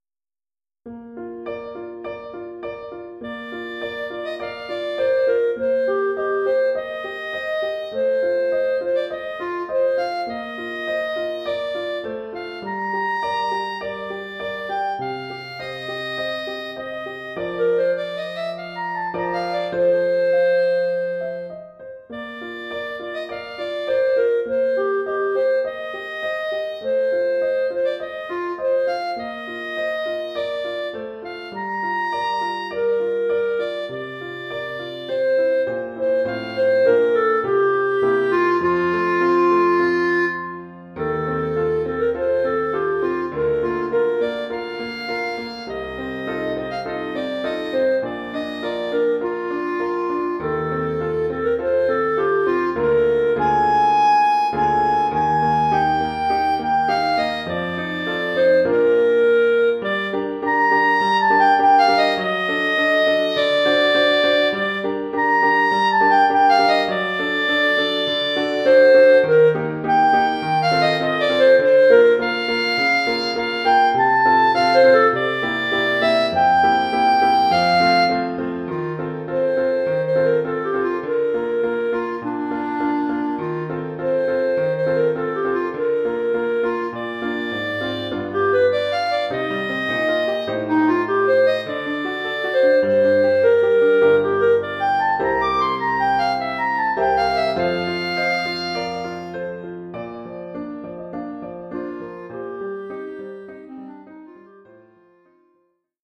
Formule instrumentale : Clarinette et piano
Oeuvre pour clarinette et piano.